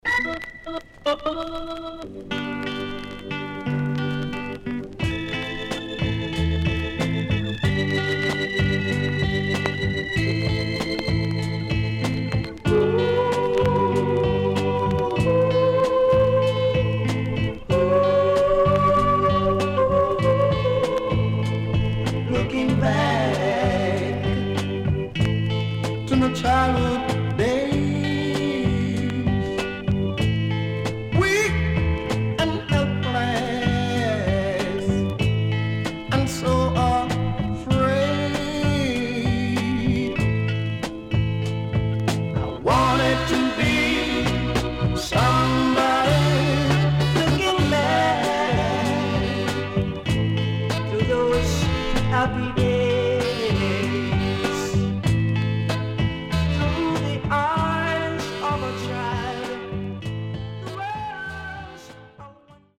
HOME > Back Order [VINTAGE 7inch]  >  EARLY REGGAE
CONDITION SIDE A:VG(OK)〜VG+
SIDE A:うすいこまかい傷ありますがノイズあまり目立ちません。